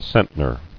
[cent·ner]